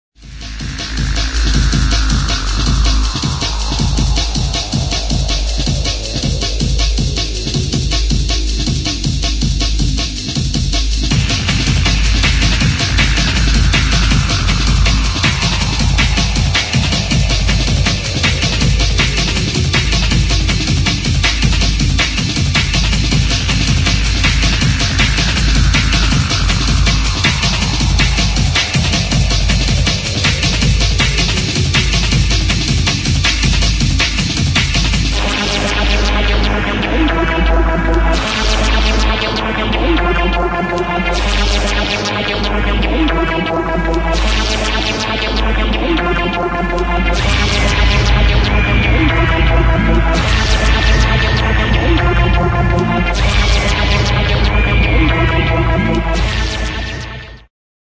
[ DRUM'N'BASS | JUNGLE ]